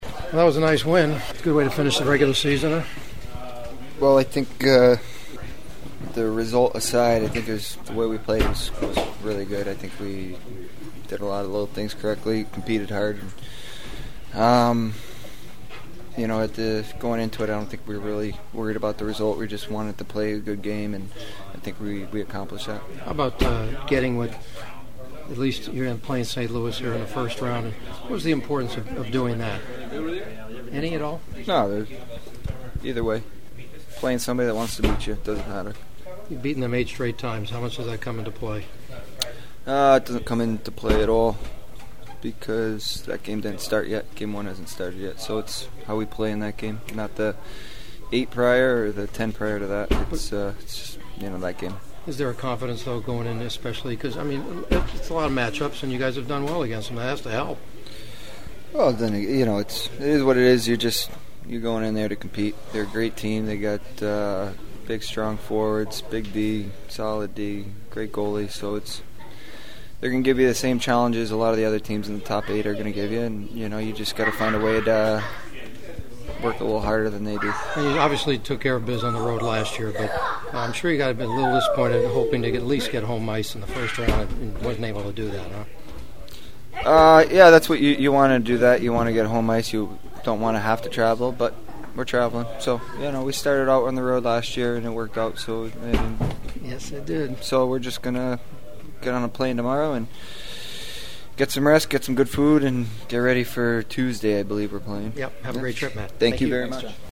Winning goalie Jonathon Quick (last year’s Conn Smythe trophy winner-as the playoffs MVP) had his usual game face on (without the mask) when I asked him about the task ahead of him…